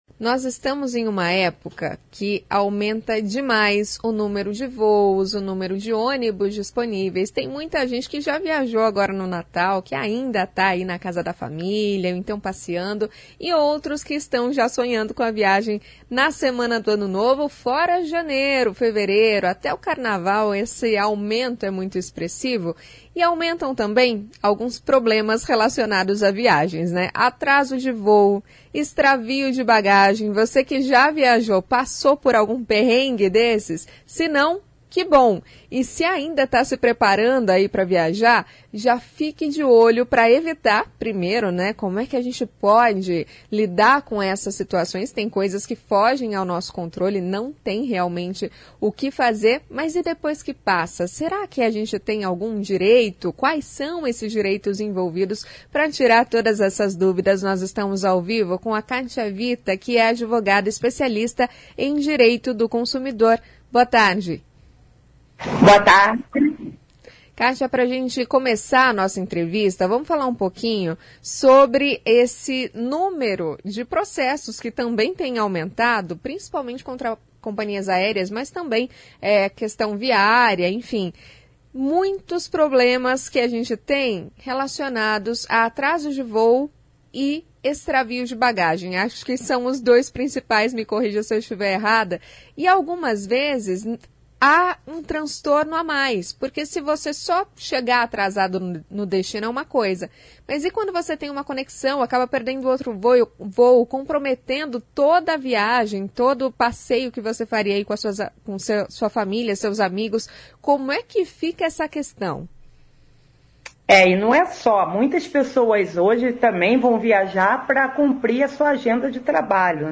A advogada especialista em direito do consumidor